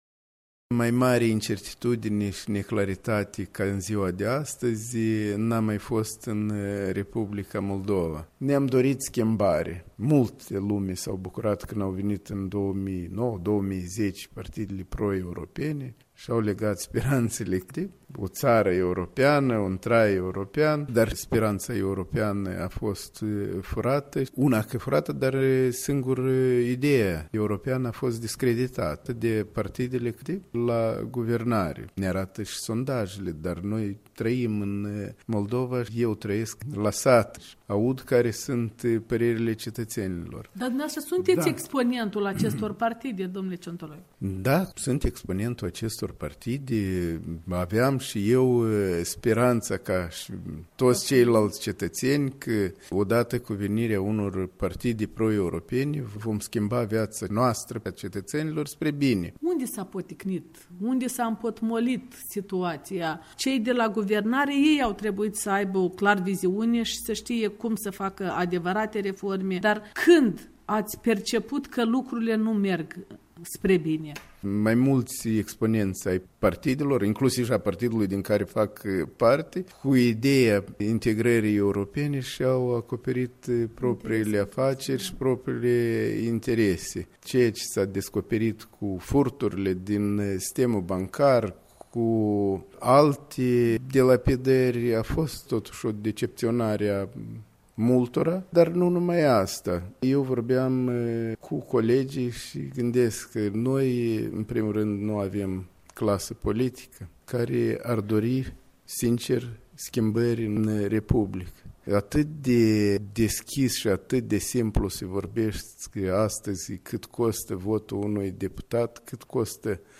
Interviu cu președintele raionului Căușeni.